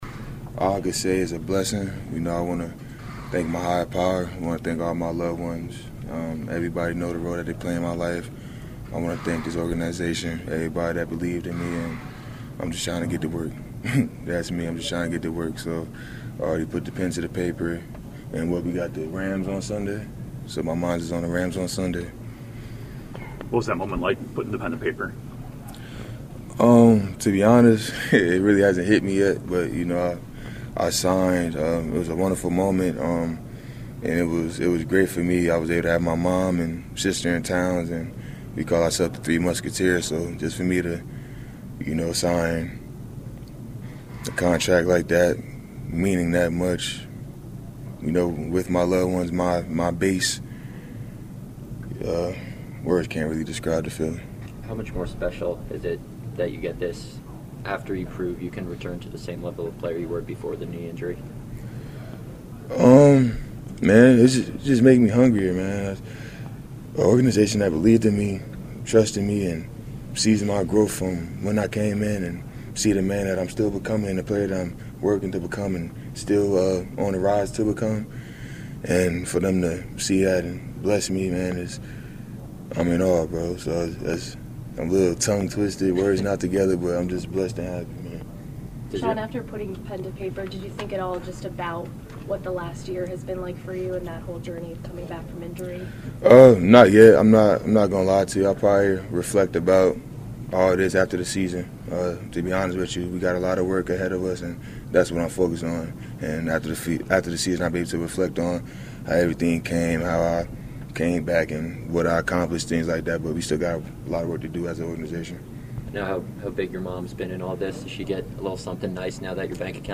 Got a firm handshake of congratulations after the media session with Rashan who was just as humble and motivated when he appeared as a guest on the 5th Quarter Show earlier this season.